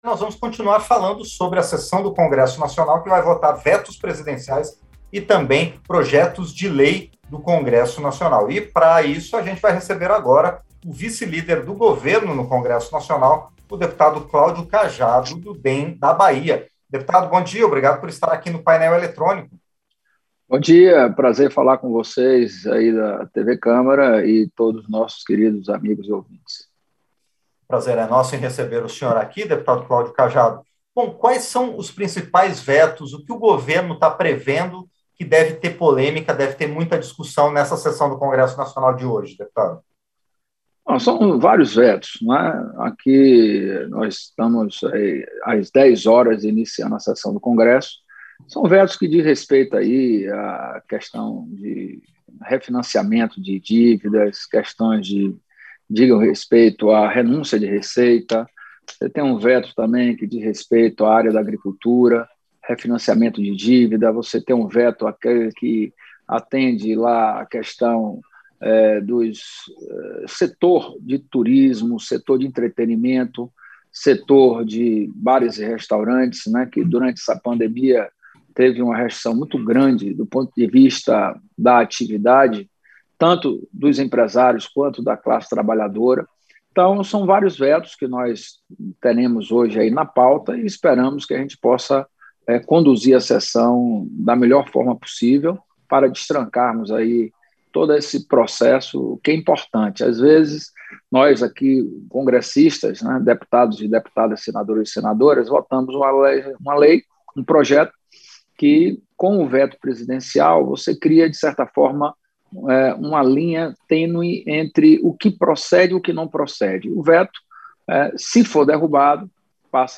Entrevista - Dep. Cláudio Cajado (PP-BA)